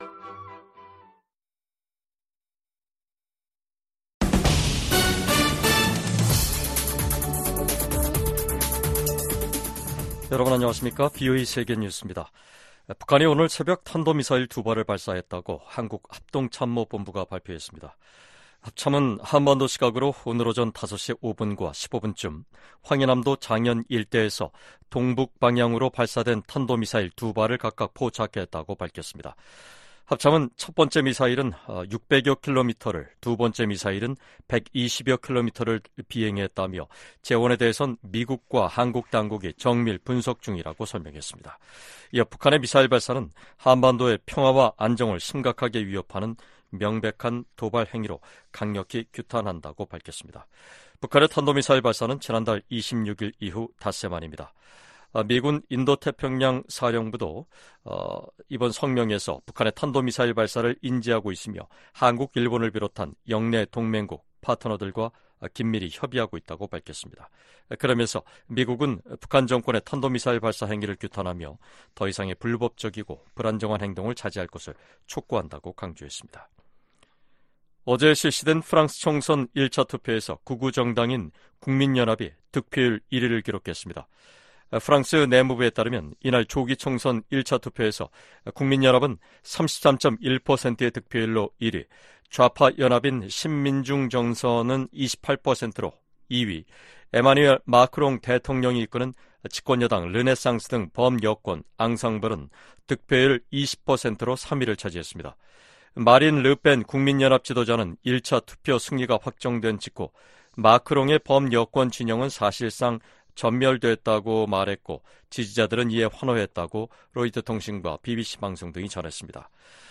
VOA 한국어 간판 뉴스 프로그램 '뉴스 투데이', 2024년 7월 1일 2부 방송입니다. 북한이 한반도 시각 1일 탄도미사일 2발을 발사했다고 한국 합동참모본부가 밝혔습니다. 유엔 안보리가 공식 회의를 열고 북한과 러시아 간 무기 거래 문제를 논의했습니다. 북러 무기 거래 정황을 노출했던 라진항에서 또다시 대형 선박이 발견됐습니다.